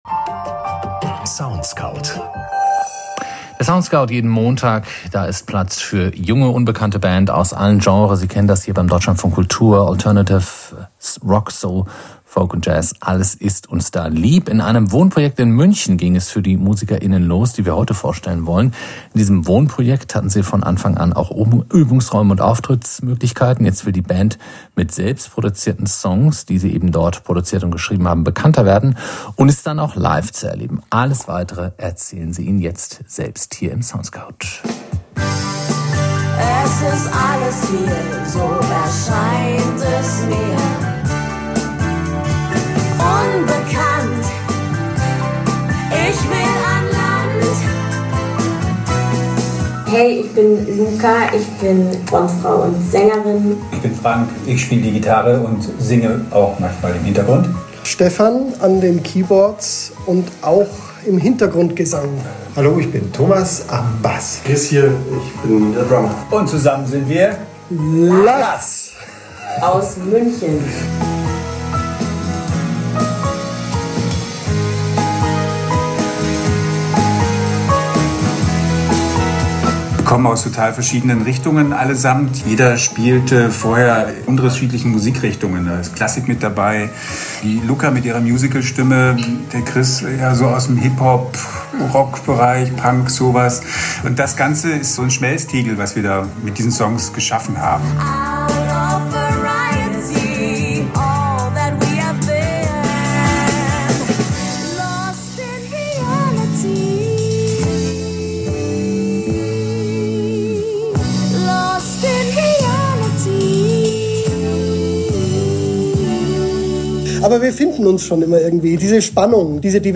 Interview mit DLF Kultur vom 03.06.2024